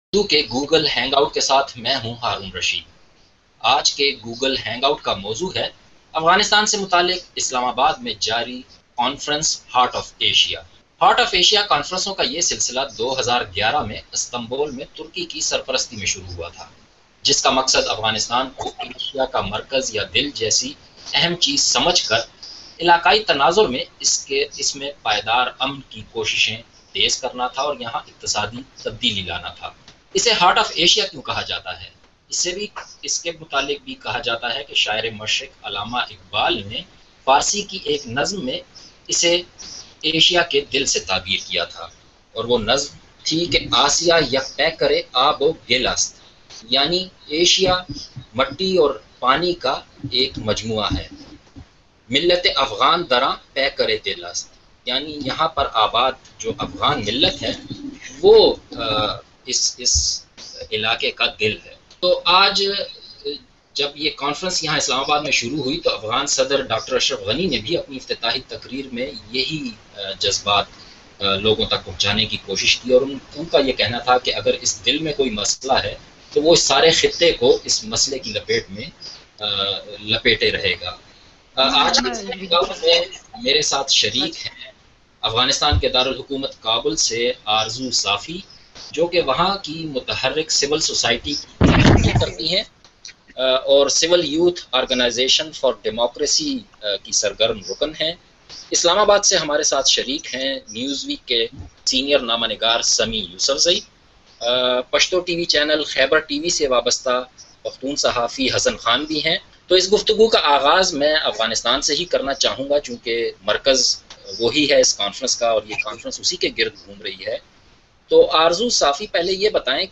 افغانستان میں امن کے حوالے سے ہارٹ آف ایشیا کانفرنس کی اہمیت پر بی بی سی اردو نے خصوصی گوگل ہینگ آؤٹ کا اہتمام کیا۔